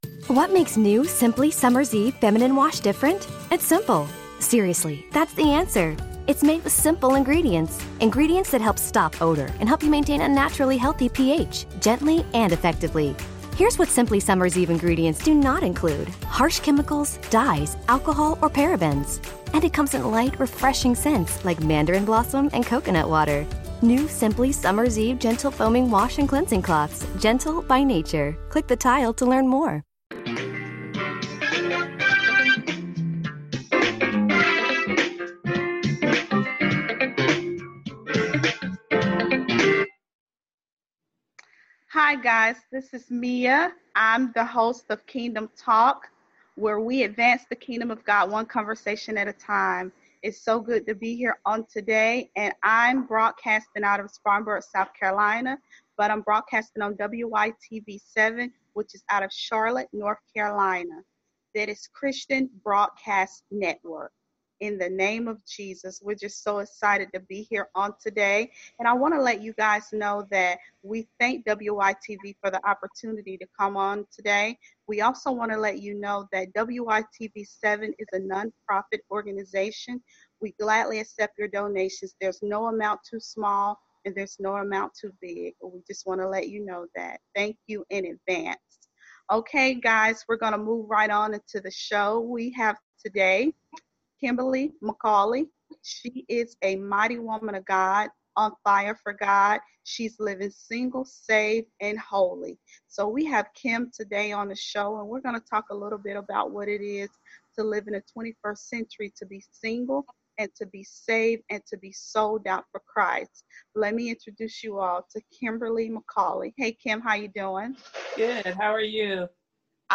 I was recently featured as a guest on WYTV7’s Kingdom Talk